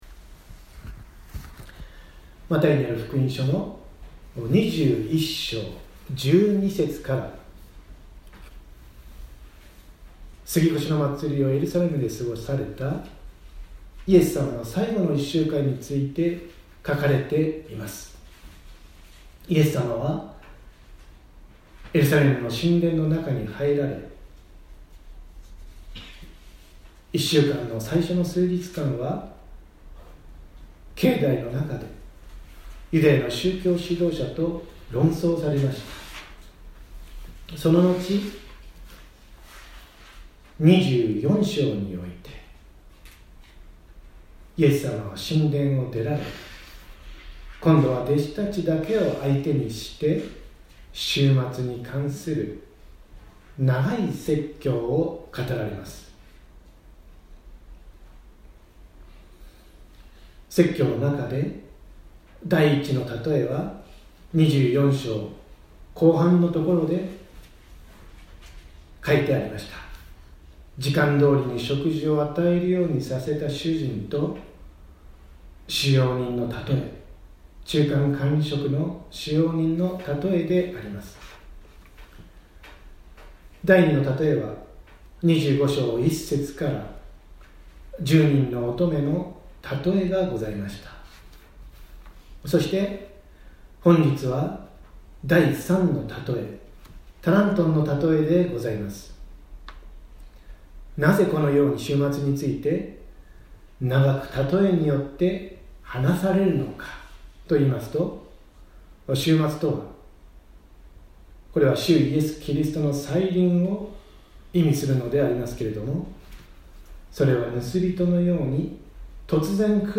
2019年03月24日朝の礼拝「タラントンの譬え 달란트의 비유」せんげん台教会
音声ファイル 礼拝説教を録音した音声ファイルを公開しています。